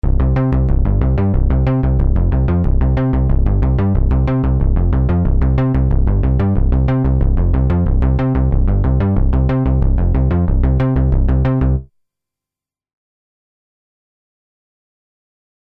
Als letztes nochmal einen Bassvergleich mit einem Sequencer-Bass.
Und Jupiter Bass:
Ich weiß nicht, ob man das bei den MP3-Beispielen hören kann: Aber für meine Ohren klingt es nur etwas „komprimierter“ und „stabiler“, sowie wie oben erwähnt höhenreicher (aber zur Abhilfe ist ja das gut klingende Filter da …).
08.JP_Bass.mp3